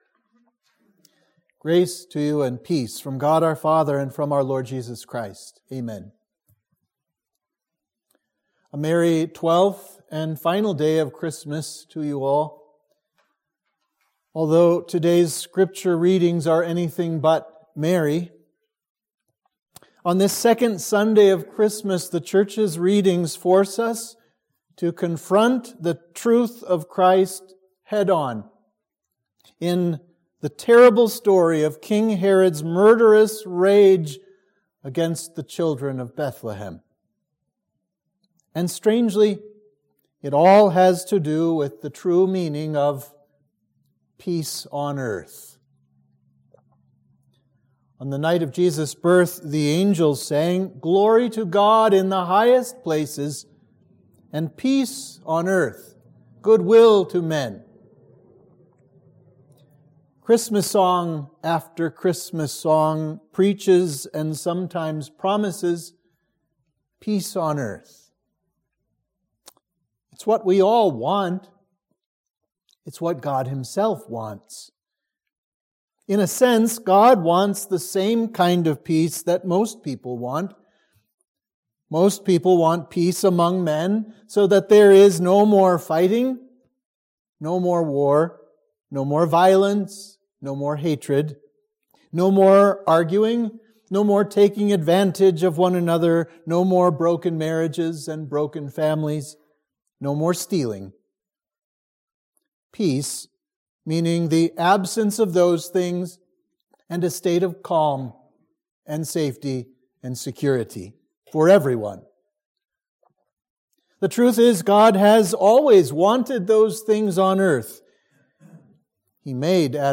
Sermon for Christmas 2